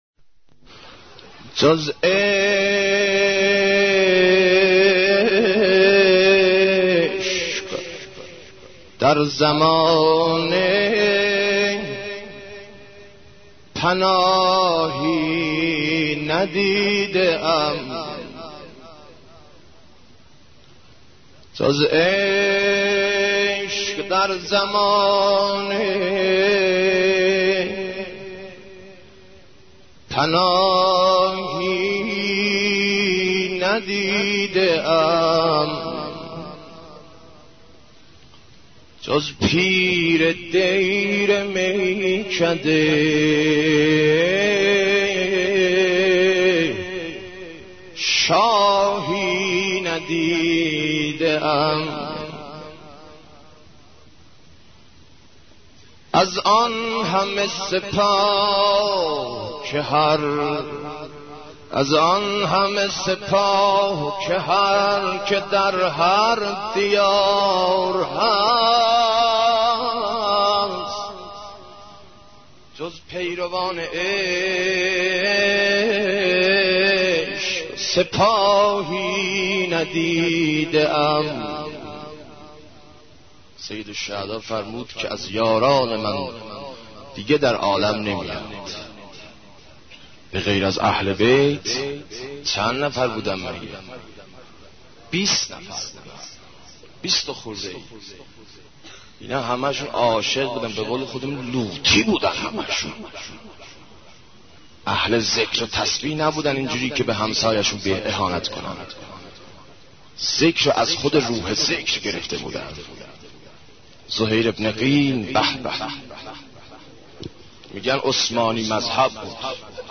مداحی امام حسین ع 11